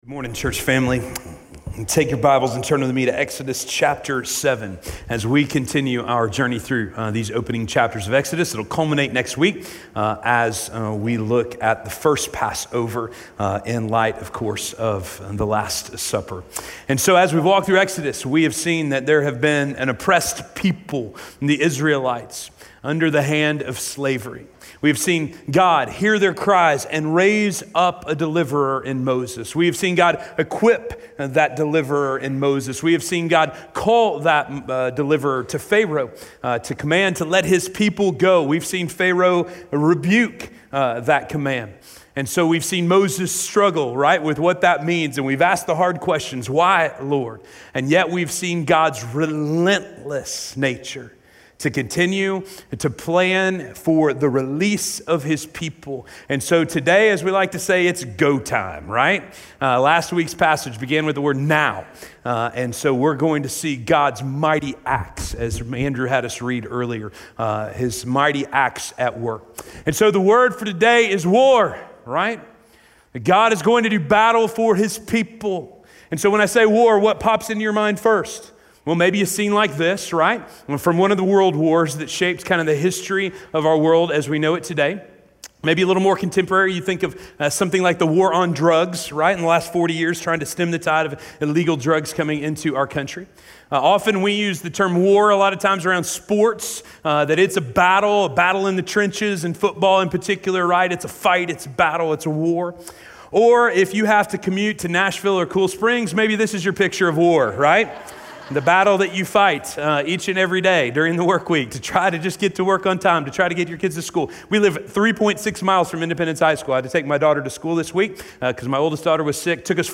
The Anticipation of Evacuation: the Plagues - Sermon - Station Hill